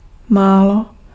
Ääntäminen
Ääntäminen France Tuntematon aksentti: IPA: /pø/ Haettu sana löytyi näillä lähdekielillä: ranska Käännös Ääninäyte Substantiivit 1. málo 2. trocha Muut/tuntemattomat 3. kousek {m} 4. troška {f} Suku: m .